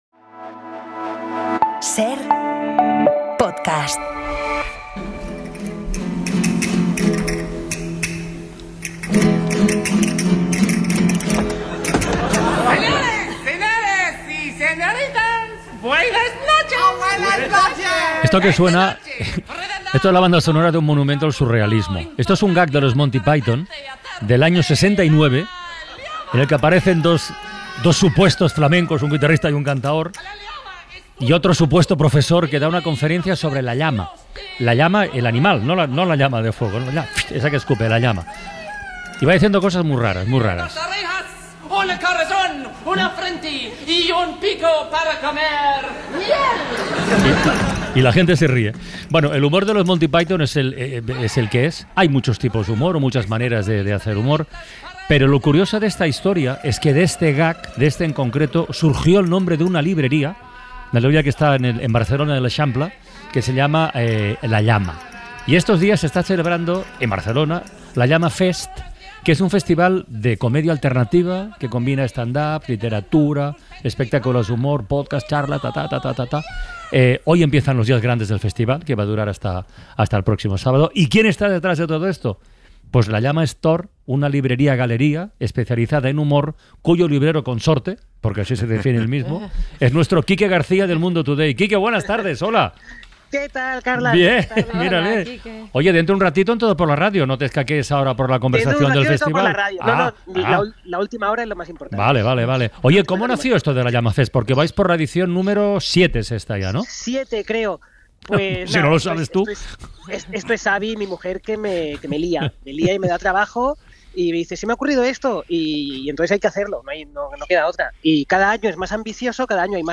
El humorista habla sobre la comedia en 'La Ventana' con motivo del inicio de la séptima edición de La Llama Fest Barcelona